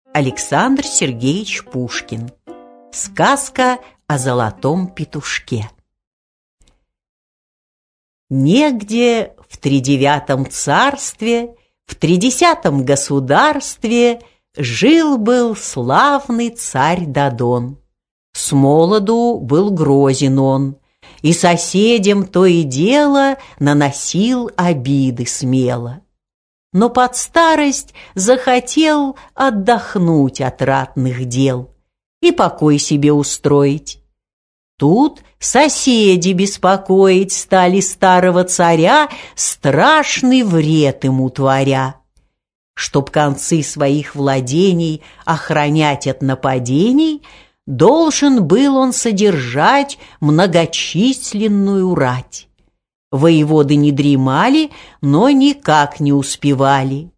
Аудиокнига Сказка о Золотом Петушке | Библиотека аудиокниг